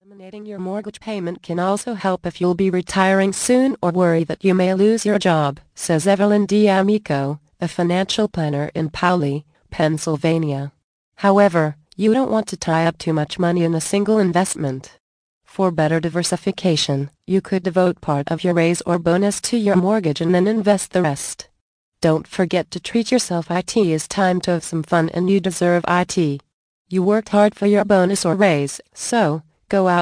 Emergency Quick Cash mp3 audio book part 4 of 4 + FREE GIFT